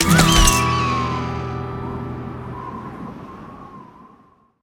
mccree-ult-sound-whistle.mp3